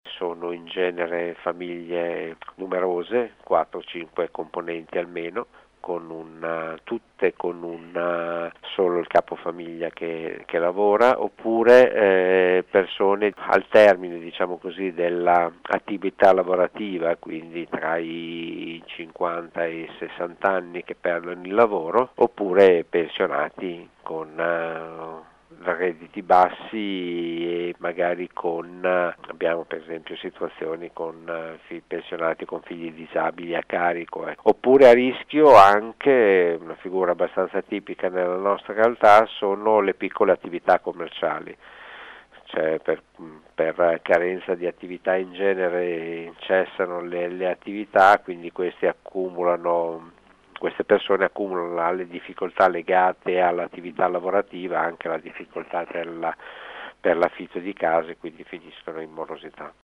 ai nostri microfoni spiega quali sono i soggetti e le famiglie che in questi giorni stanno subendo gli sfratti: